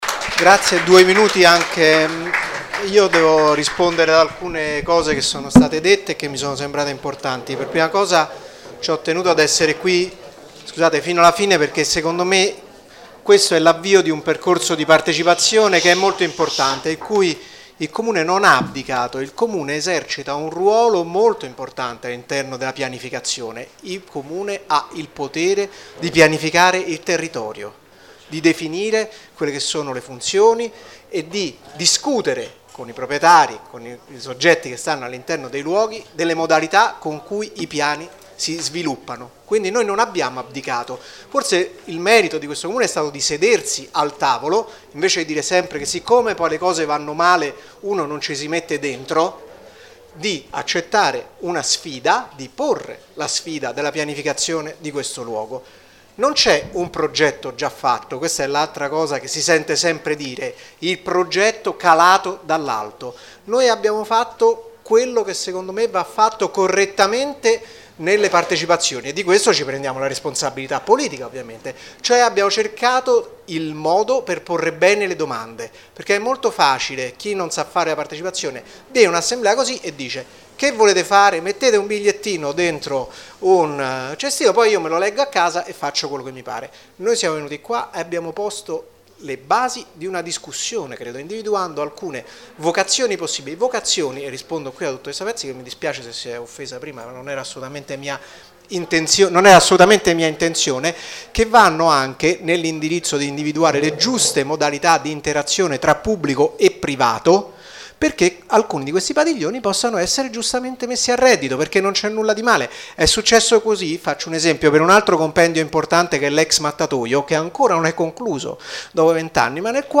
Risposte e considerazioni finali
Luca Montuori - Assessore all'Urbanistica di Roma Capitale